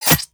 hit3.wav